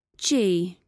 Коллекция включает четкое произношение всех 26 букв, что идеально подходит для обучения детей или начинающих.
Gg dʒi